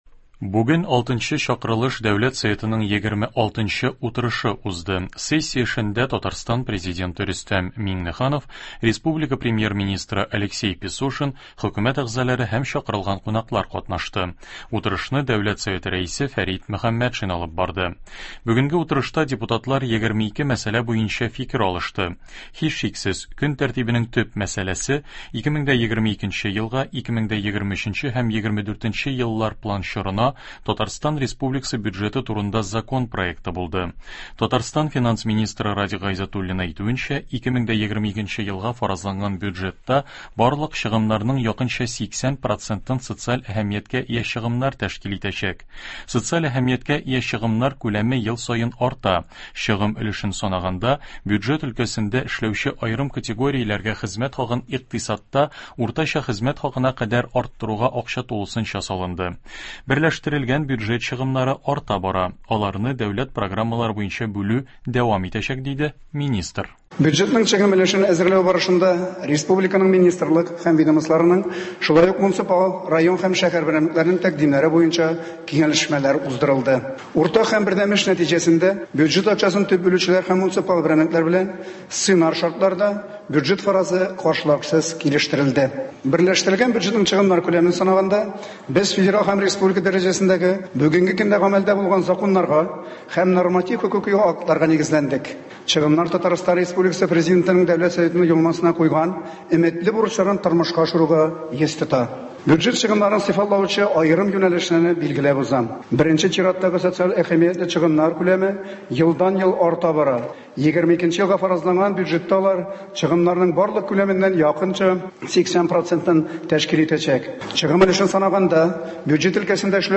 Татарстан Республикасы Дәүләт Советы утырышыннан радиоотчет.
Радиоотчет о заседании Госсовета РТ.